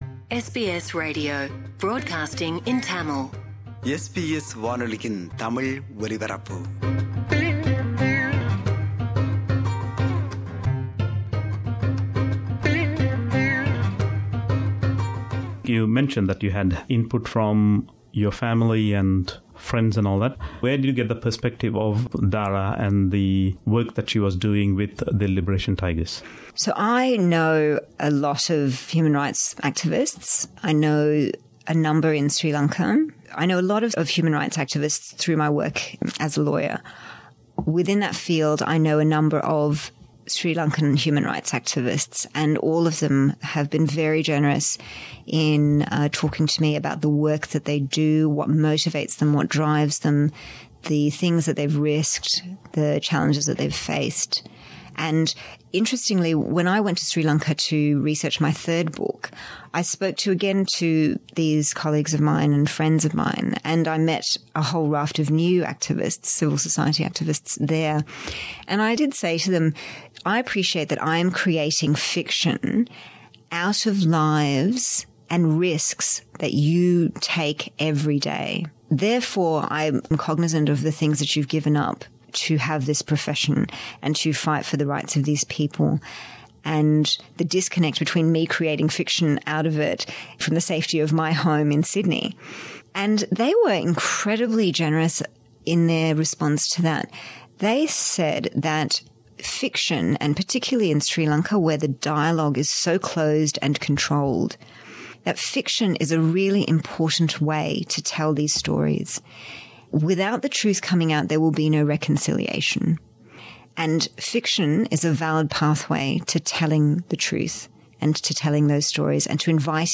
This is the final part of the two-part interview.